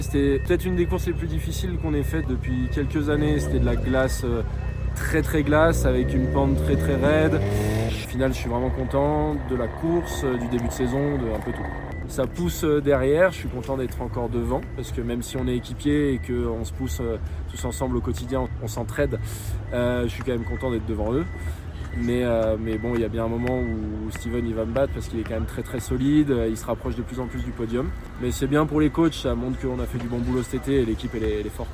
Clément Noël, leader provisoire de la coupe du monde de ski alpin revient sur ce début de saison canon en slalom pour les Français :